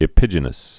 (ĭ-pĭjə-nəs)